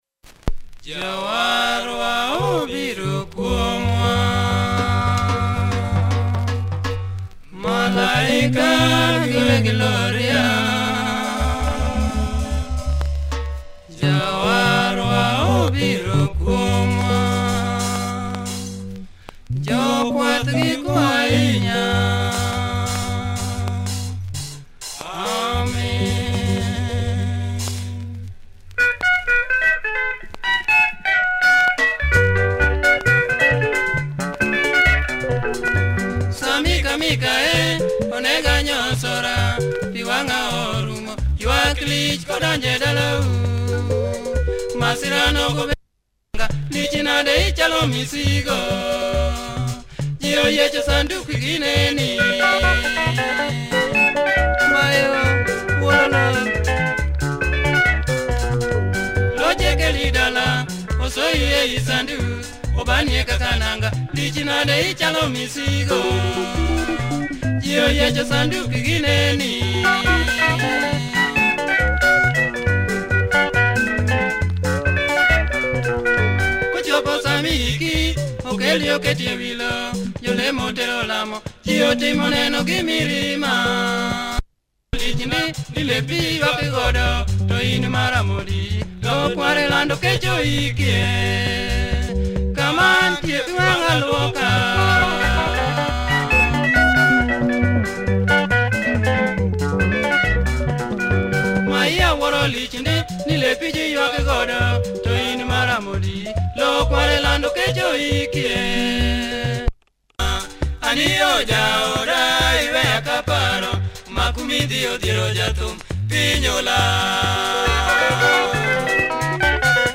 Tight LUO benga